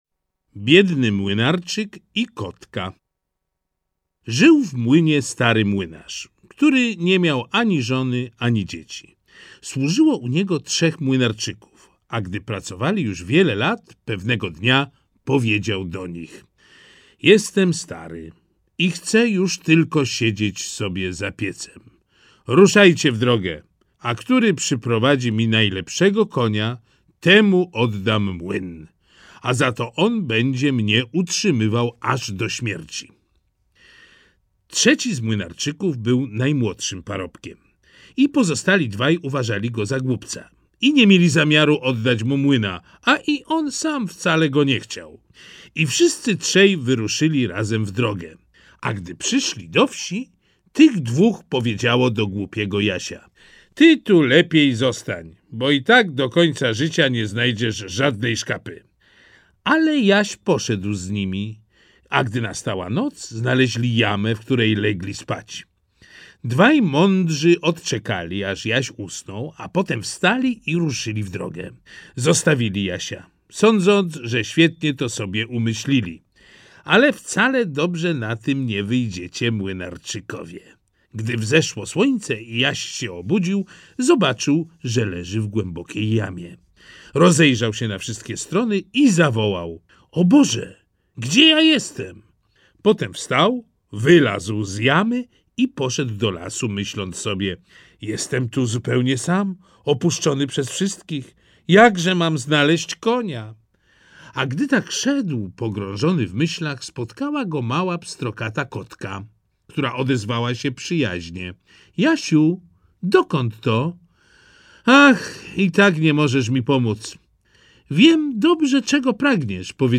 Baśnie braci Grimm 4 - Jakub Grimm, Wilhelm Grimm - audiobook